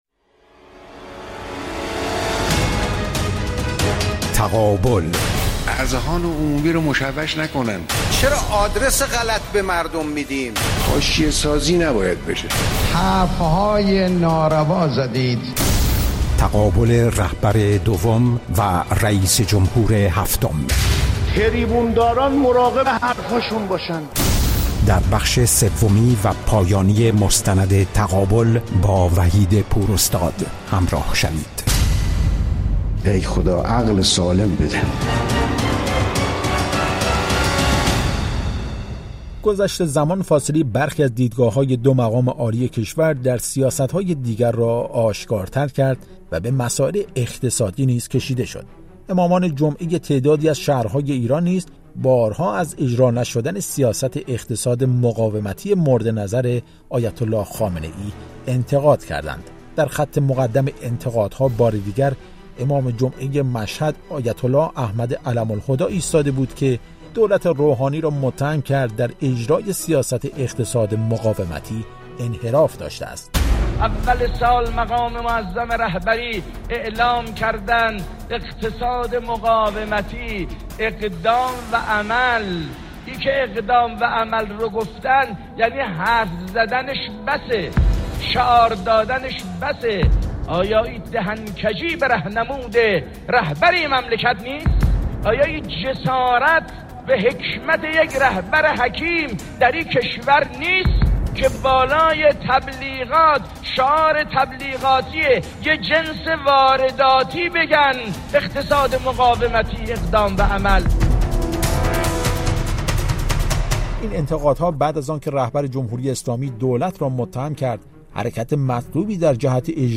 مستند رادیویی «تقابل»
مستند رادیویی تقابل/ بخش اول